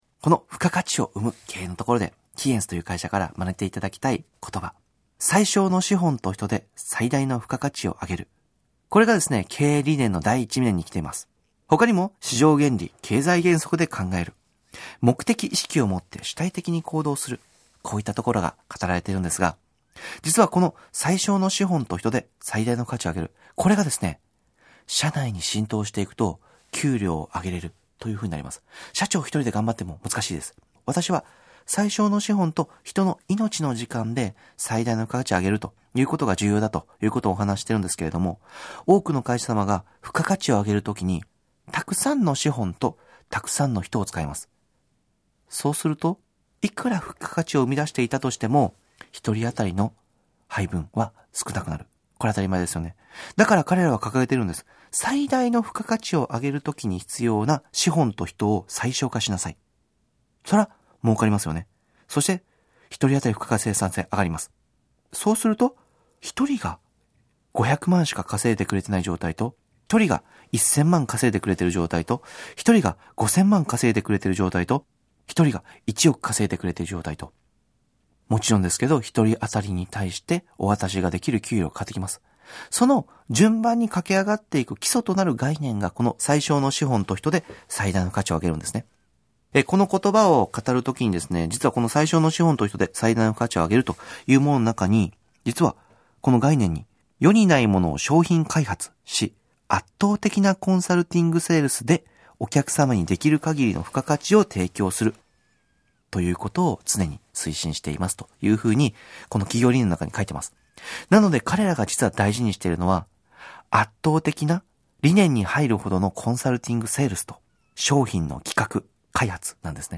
「付加価値を生む経営」講話のサンプル音声をお聴きいただけます。